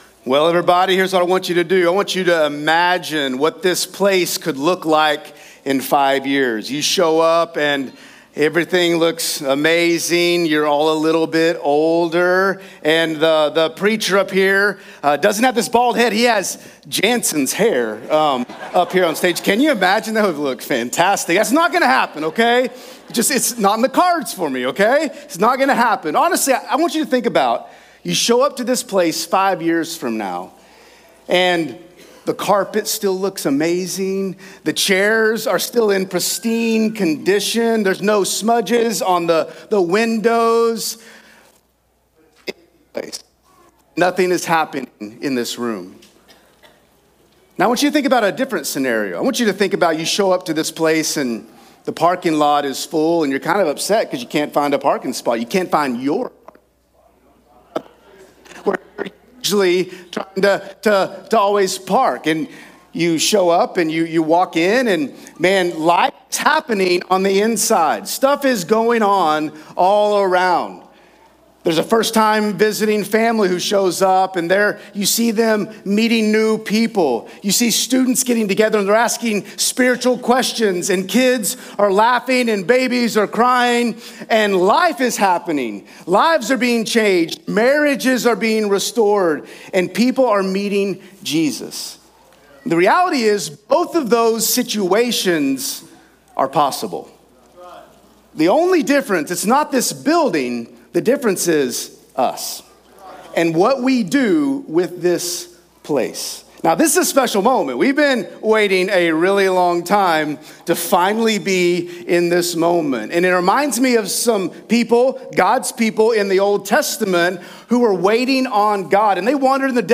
Sermons | Central Baptist Church Owasso
A day to celebrate Central's Grand Re-Opening